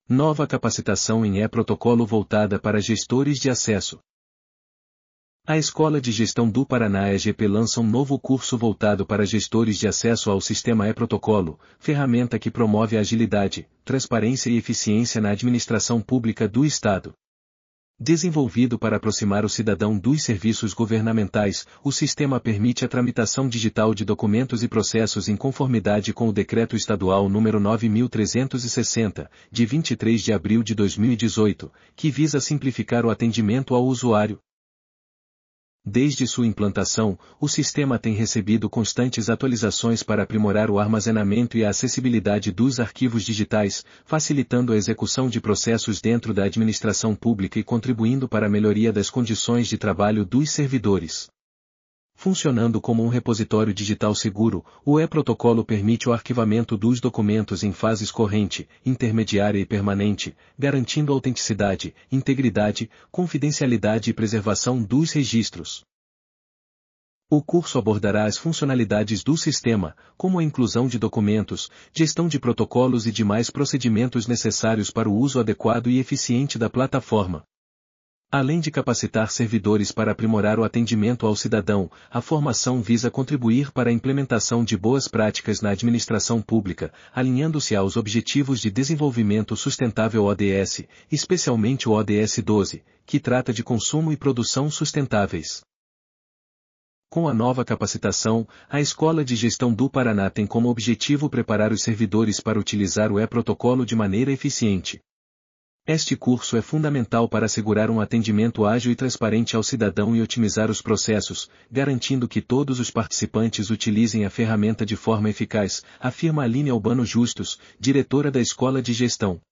audionoticia_capacitacao_em_eprotocolo_voltada.mp3